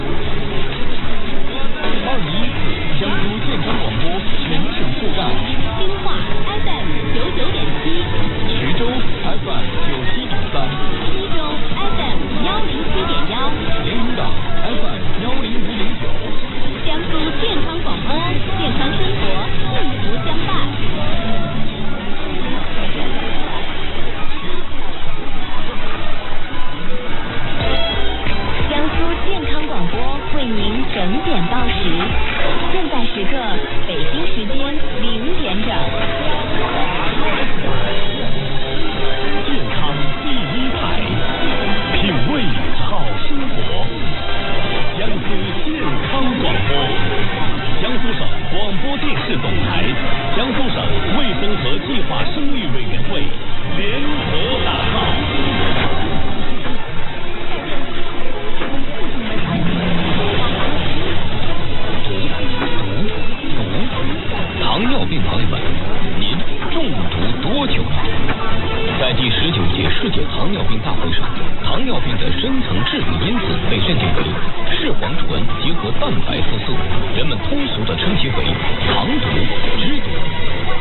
Eli terveyskanava. Kerrassaan hämmästyttävä kuuluvuus asemalle, jota ei ole aiemmin OLLiin lokattu ja joka on kuitenkin ollut listoissa jo vuosikaudet.